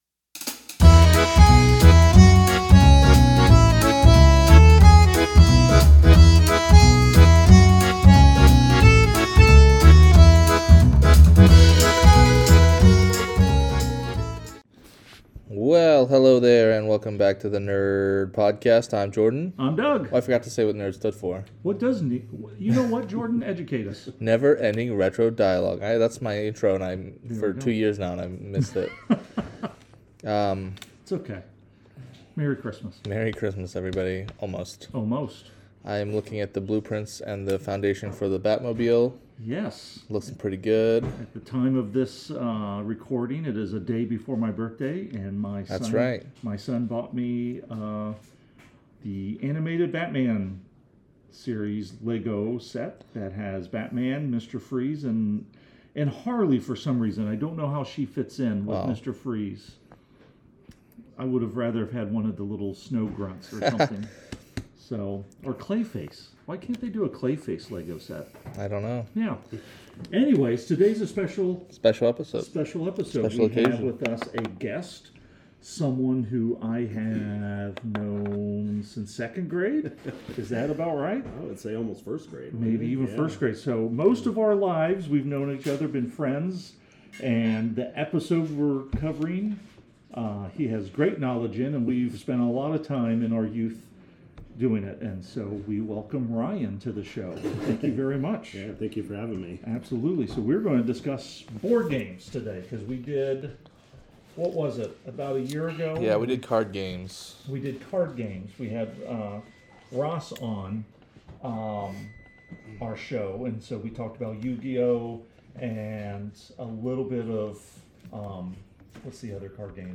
Two old friends talk about all things Nerdy! From comics to cartoons, video games and movies, and more!